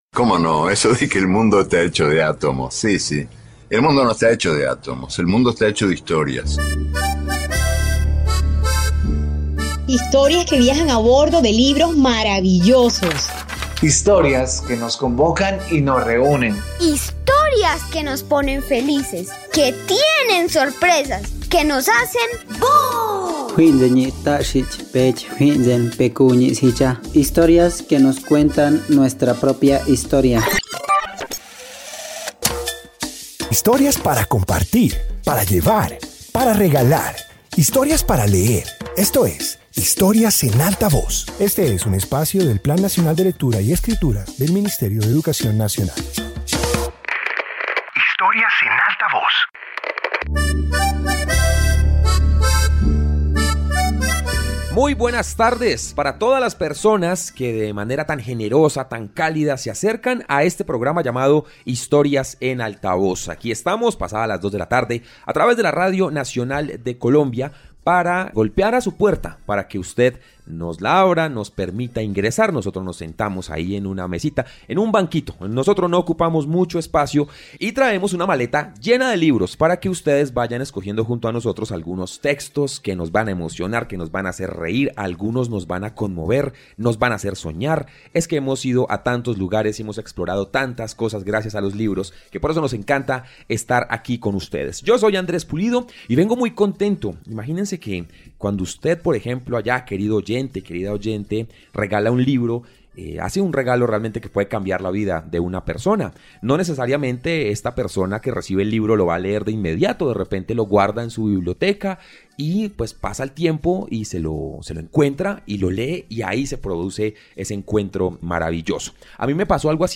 Introducción Este episodio de radio presenta obras creadas por autoras colombianas actuales. Comparte fragmentos y relatos que muestran diversas voces, estilos y miradas sobre el mundo desde la literatura escrita por mujeres.